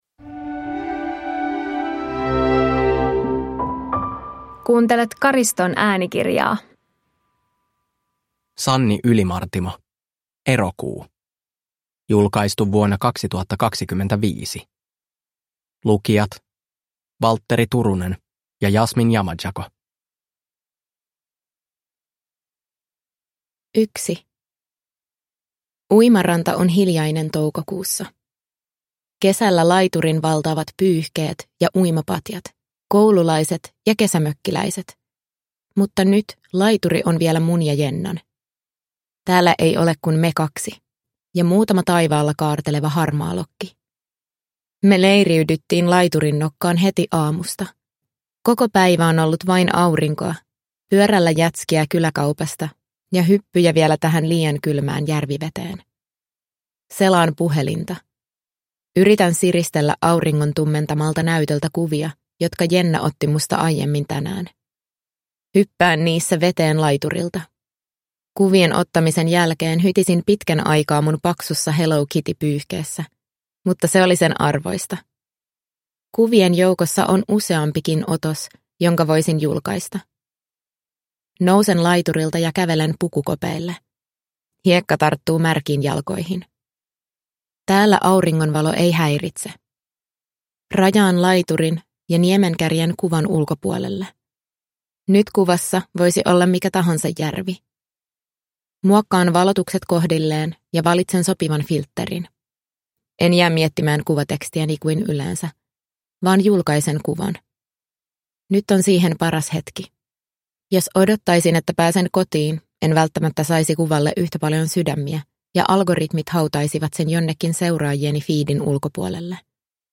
Erokuu – Ljudbok